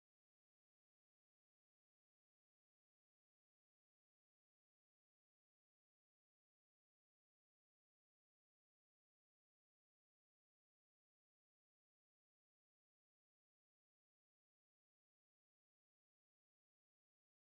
Kindertänze: Mariechen auf dem Stein
Tonart: C-Dur
Taktart: 4/4
Tonumfang: große Sexte